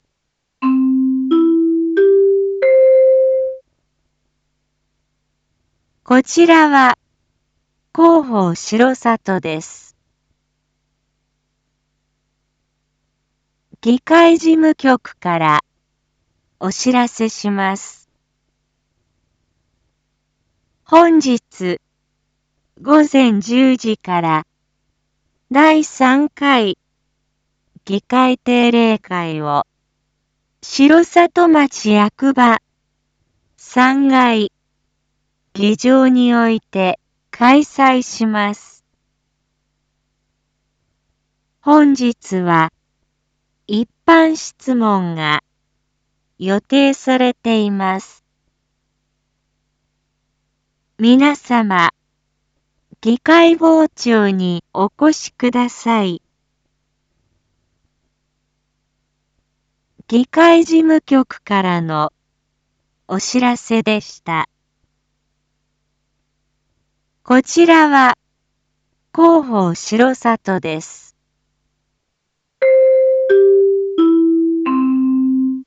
一般放送情報
Back Home 一般放送情報 音声放送 再生 一般放送情報 登録日時：2023-09-12 07:01:17 タイトル：9/12 7時 第3回議会定例会 インフォメーション：こちらは広報しろさとです。